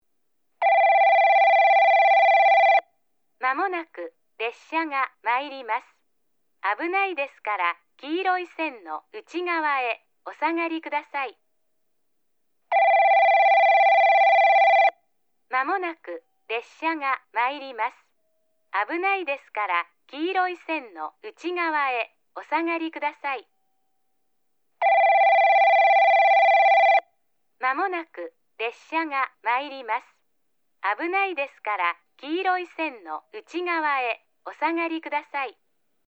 接近放送　女声